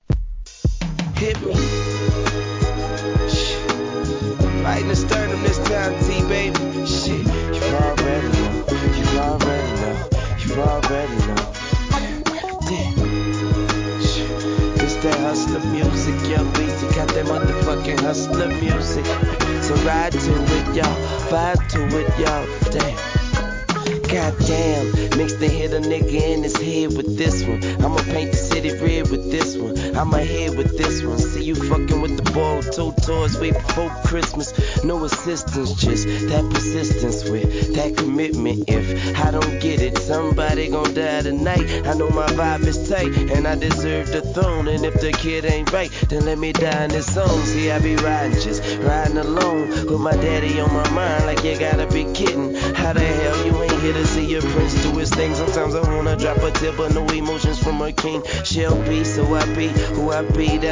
HIP HOP/R&B
ブルージーなメロ〜作品!!